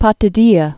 (pŏtĭ-dēə)